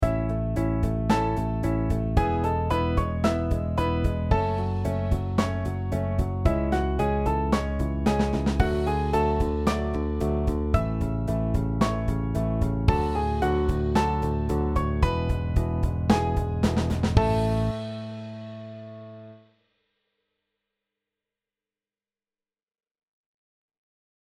Piano Pop
This example features a longer level and a different chord progression.
You can see how much accompaniment style changes the feel of the song as both examples actually have the same melody!